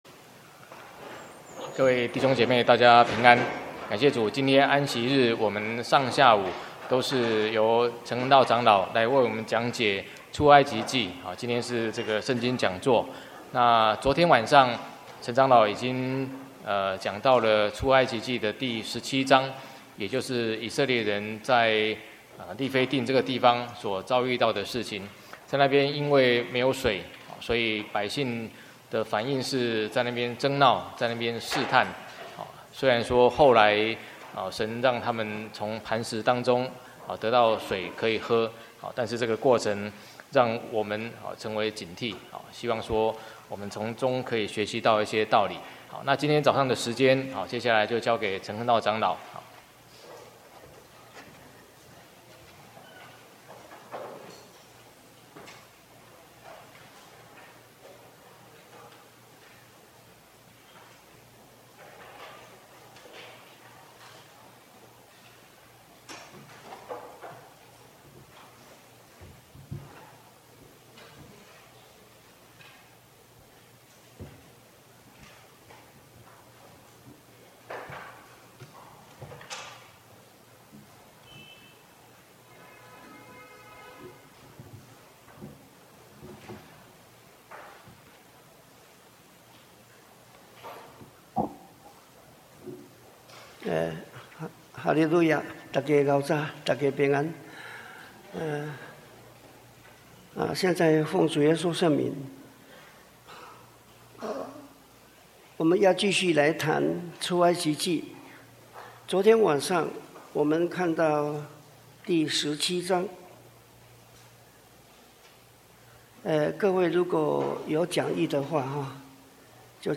聖經講座：出埃及記(十三)-講道錄音